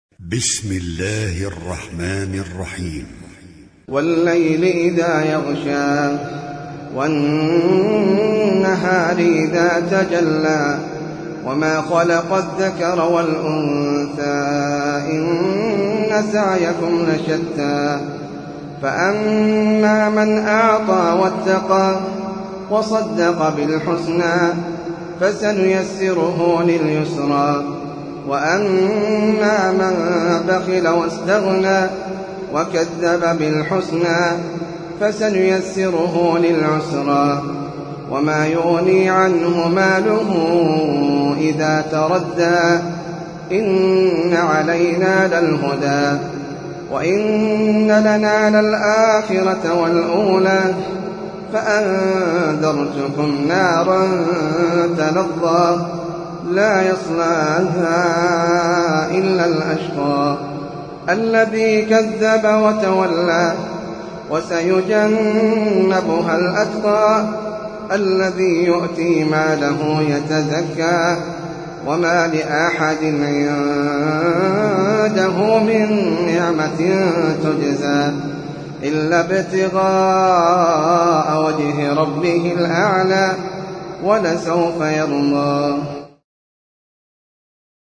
سورة الليل - المصحف المرتل (برواية حفص عن عاصم)
جودة عالية